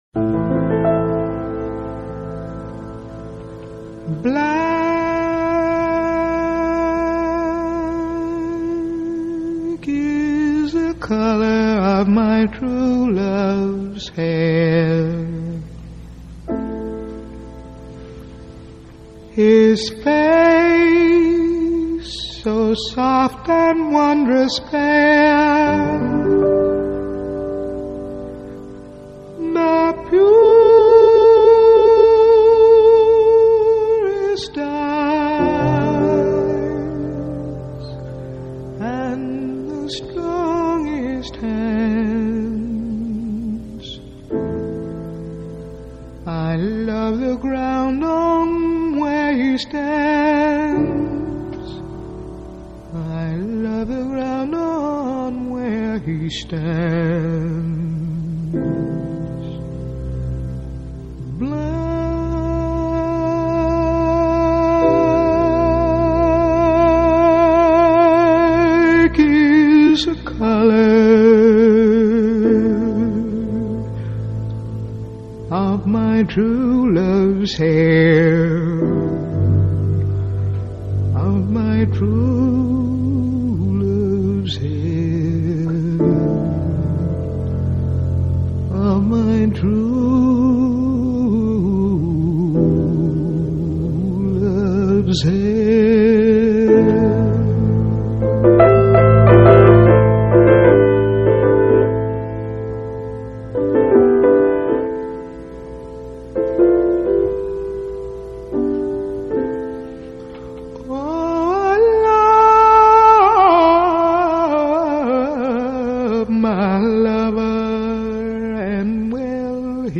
Jazz, Soul, Live